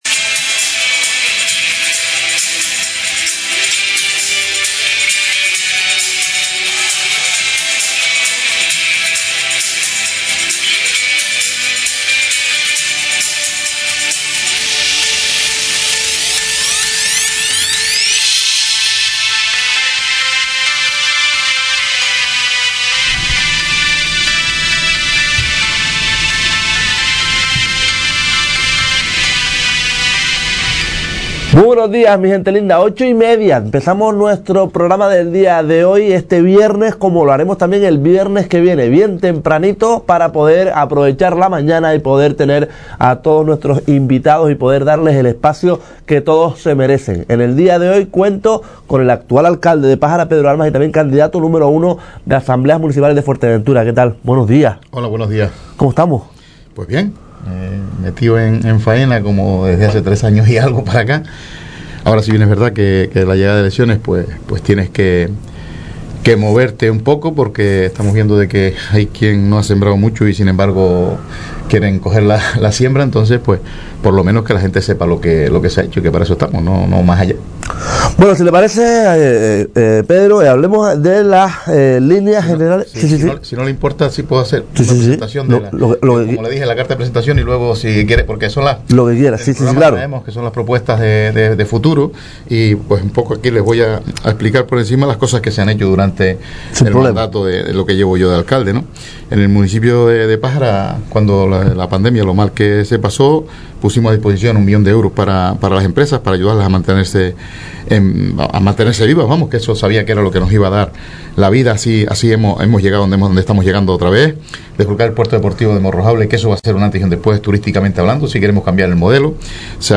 El pasado viernes nos visitaba el candidato Nº1 a la alcaldía de Pájara, Pedro Armas Romero. Ya puedes volver a escuchar la entrevista o descargarla.